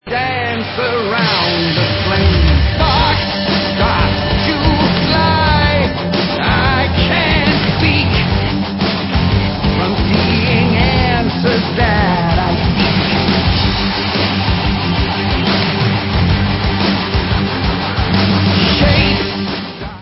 Legendární rocková formace